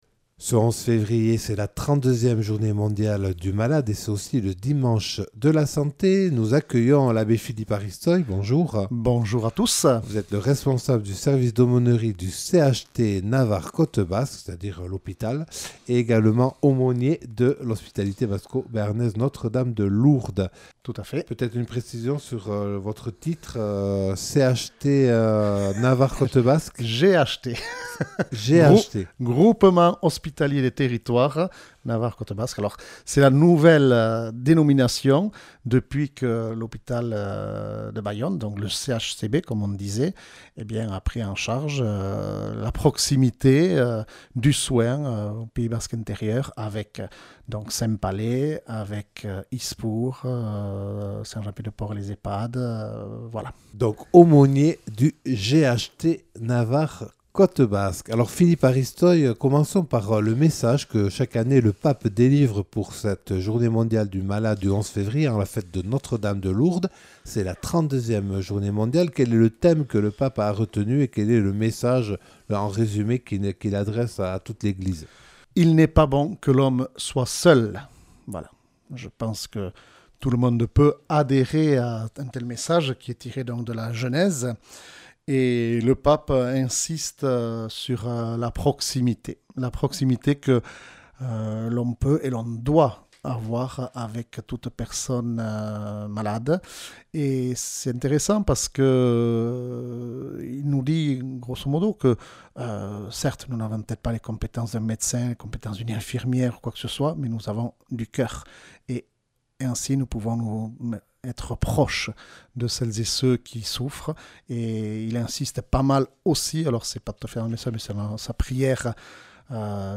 Accueil \ Emissions \ Infos \ Interviews et reportages \ 32e Journée Mondiale du Malade et Dimanche de la Santé le 11 février (...)